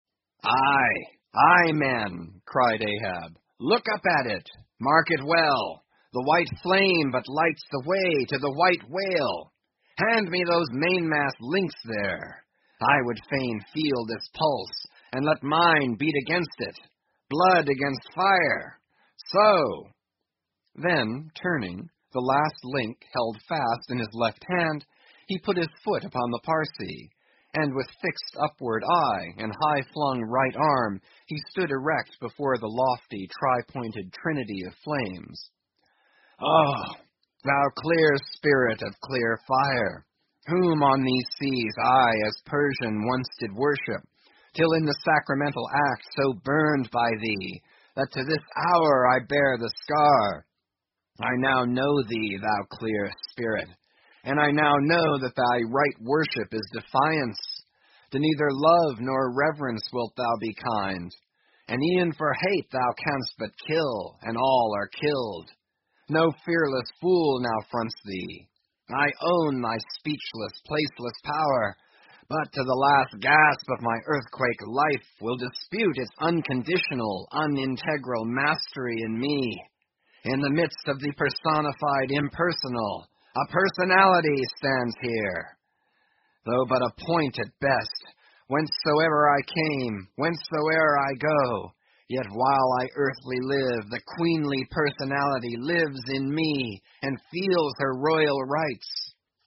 英语听书《白鲸记》第944期 听力文件下载—在线英语听力室